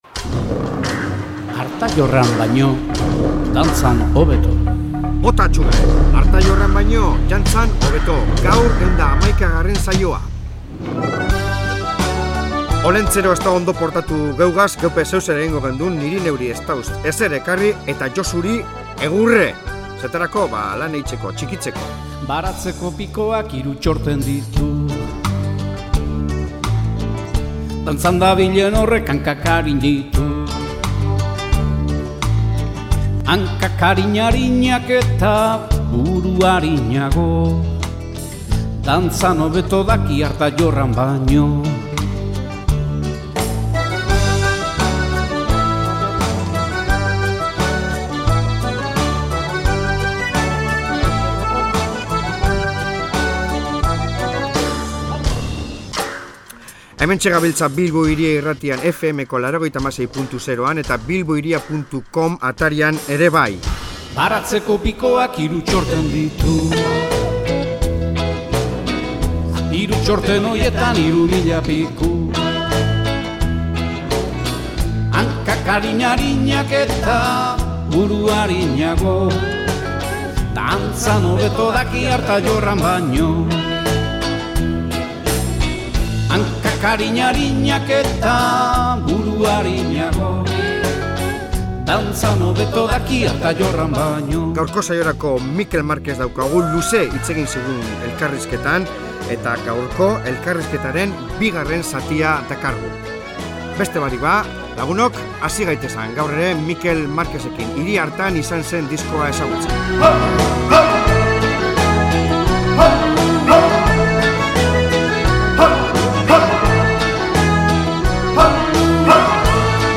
Elkarrizketa patxadatsu eta interesgarria eduki dugu